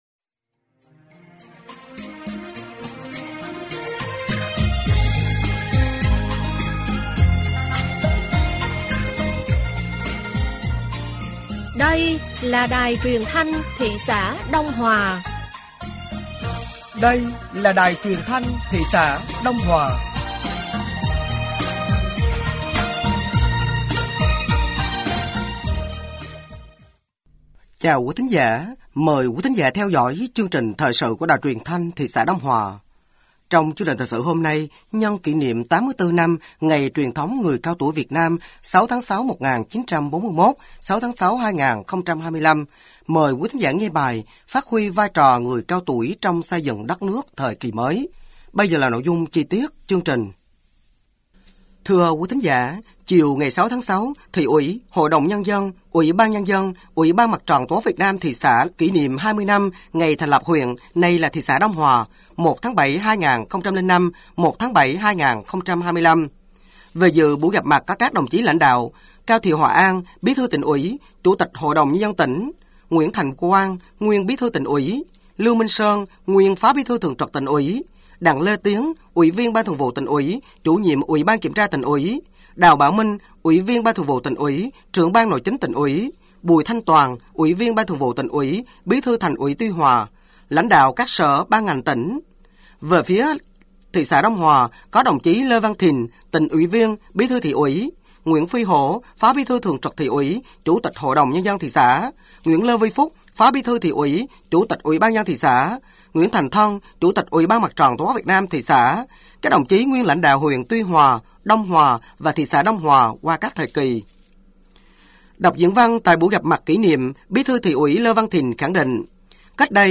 Thời sự tối ngày 6/6 sáng ngày 7/6/2025